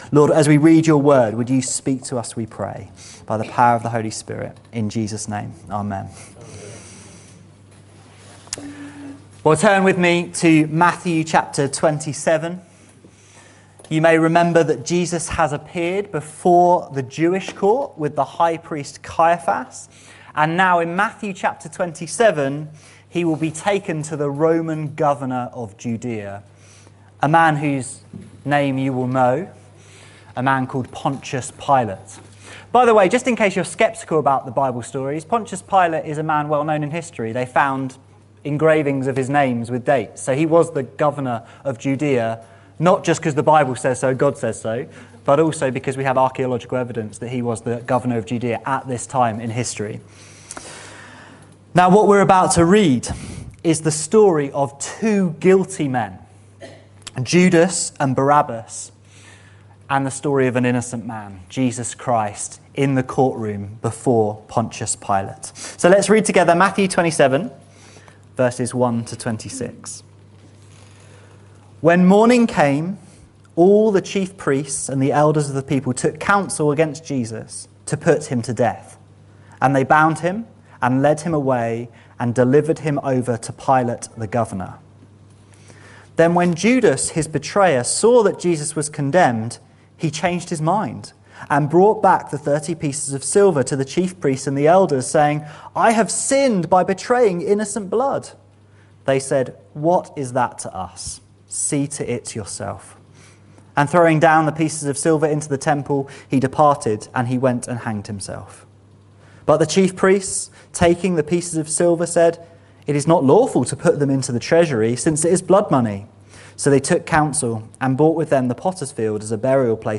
This sermon proclaims the good news of the Gospel for all!